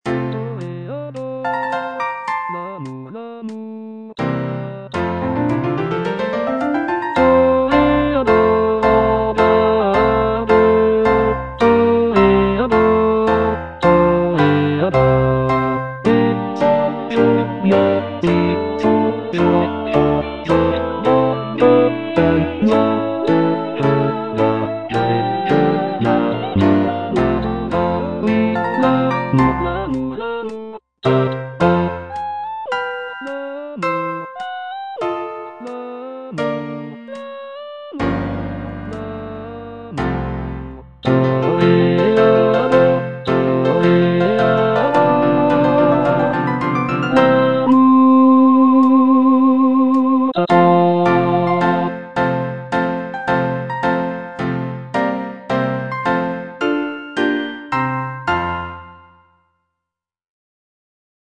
G. BIZET - CHOIRS FROM "CARMEN" Toreador song (II) (bass I) (Voice with metronome) Ads stop: auto-stop